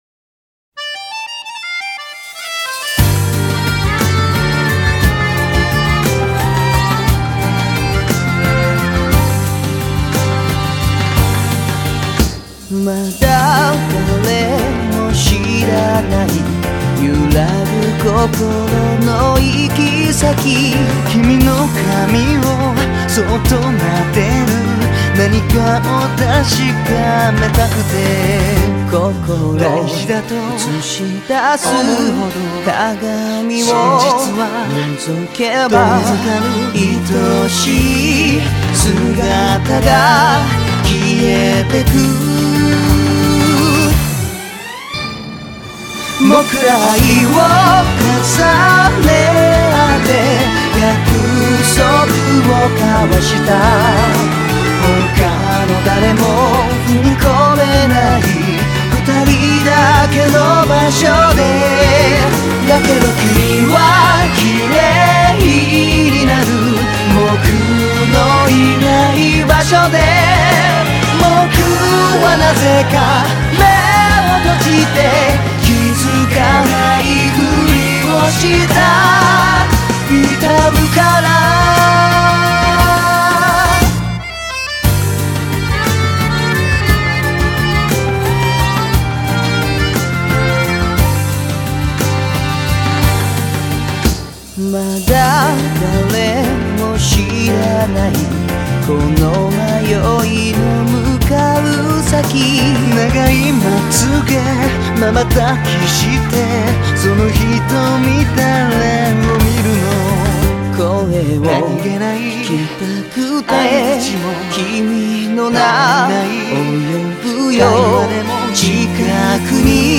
눈물나는 멜로디에, 살인적인 가사에...... 이건 나를 두 번 죽이는 일이야 TㅁT!!!